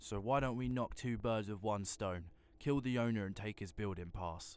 Voice Lines